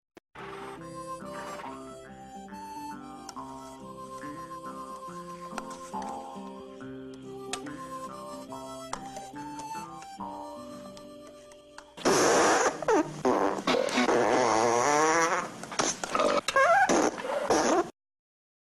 Blazing Saddles Farting